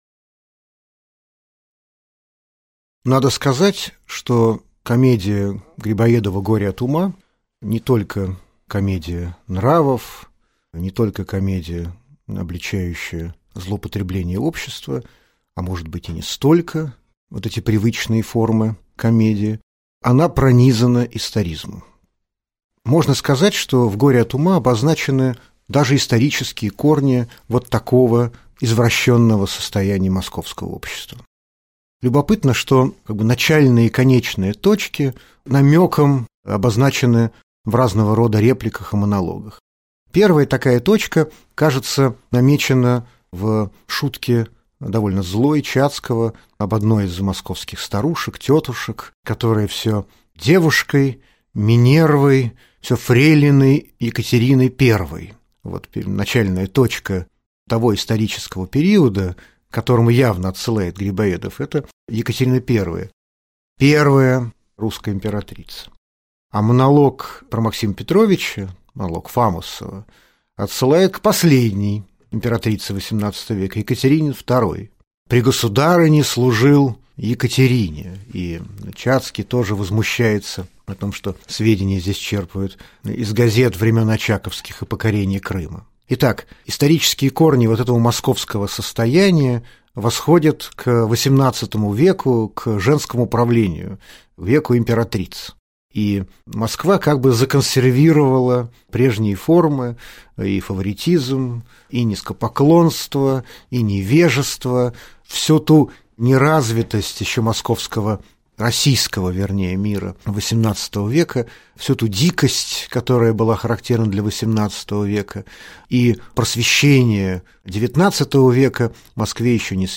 Аудиокнига Лекция «Горе от ума». Умен ли Чацкий?»